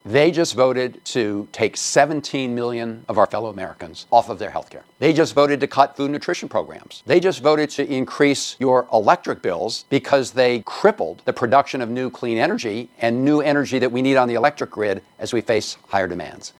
Maryland US Senator Chris Van Hollen quickly issued a video statement following the passage of what he calls the “Big Ugly Betrayal.”